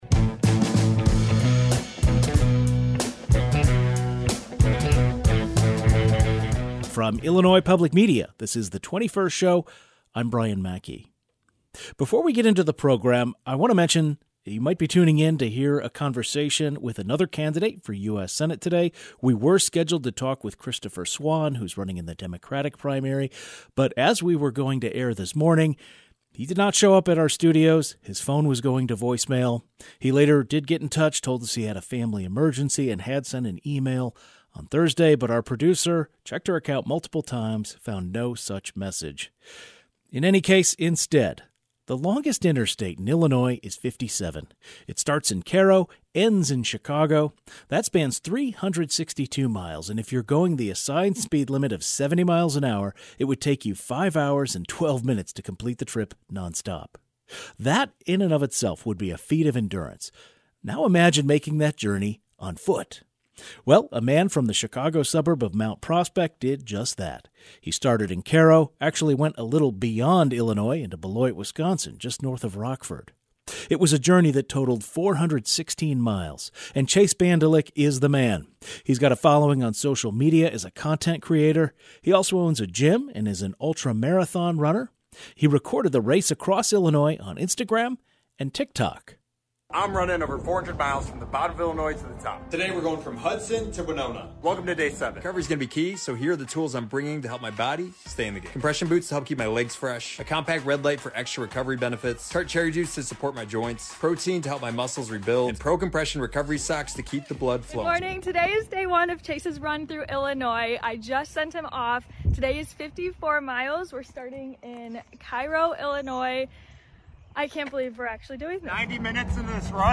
We talk with him about the journey.